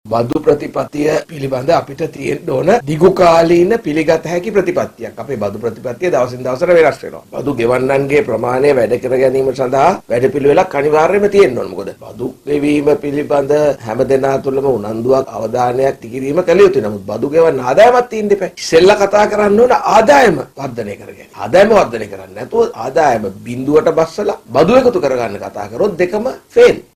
මාධ්‍ය හමුවකට එක්වෙමින් පාර්ලිමේන්තු මන්ත්‍රී චන්දිම වීරක්කොඩි මහතා මේ බව අවධාරණය කළා.